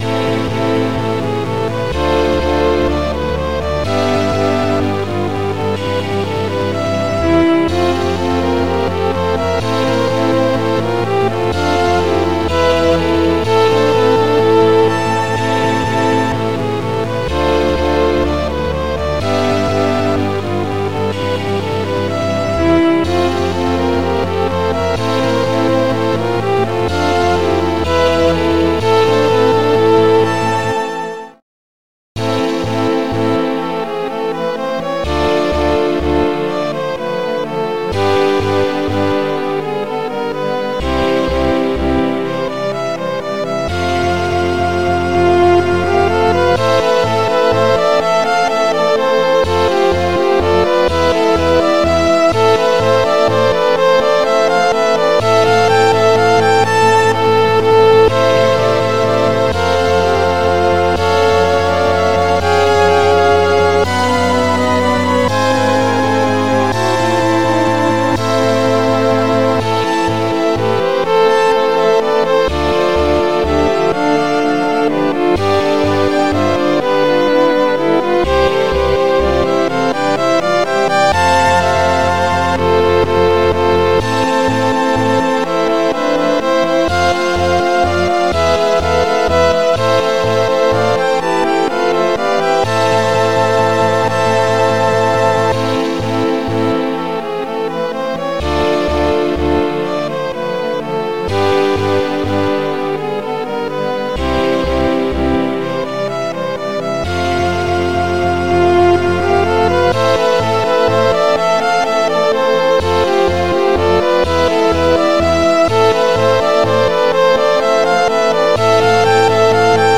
Extended Module
2 channels
Tracks 8 Samples 3 Patterns 10 Instruments Pipey thingys 303:Violin 1-B